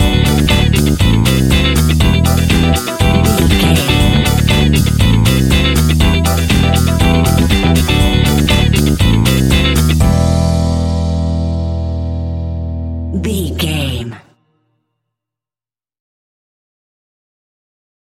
Aeolian/Minor
groovy
uplifting
energetic
drums
bass guitar
electric piano
electric guitar
brass
disco house
electronic funk
upbeat
synth leads
Synth Pads
synth bass
drum machines